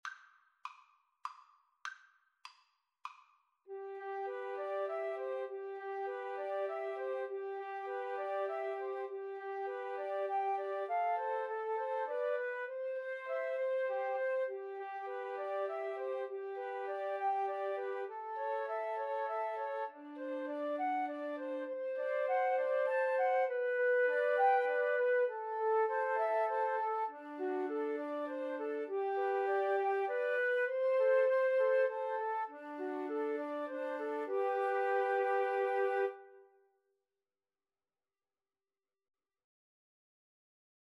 Play (or use space bar on your keyboard) Pause Music Playalong - Player 1 Accompaniment Playalong - Player 3 Accompaniment reset tempo print settings full screen
17th-century English folk song.
G major (Sounding Pitch) (View more G major Music for Flute Trio )
3/4 (View more 3/4 Music)
Moderato